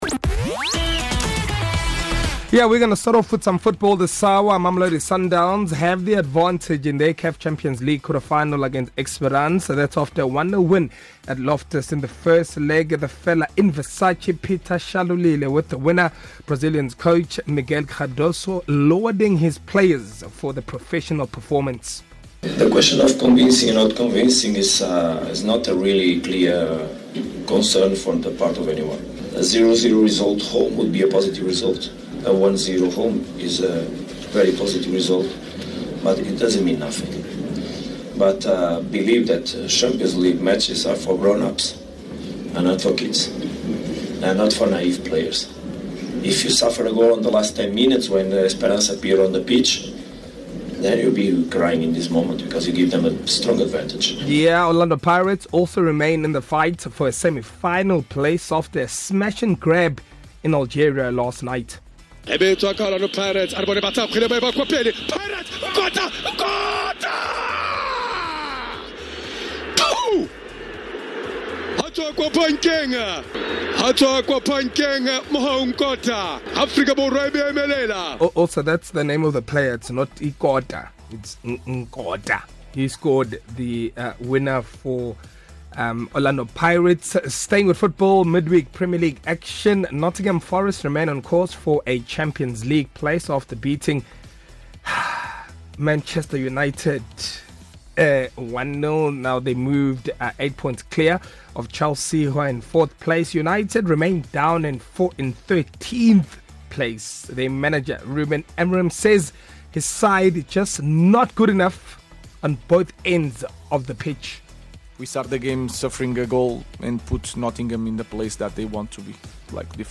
Stay up-to-date with the latest sports news from KwaZulu-Natal, South Africa and around the world with these podcasts from the East Coast Radio Sports team. Bulletin updates are: Mon-Fri - 6h30, 7h30, 8h30, 15h30, 16h30, 17h30.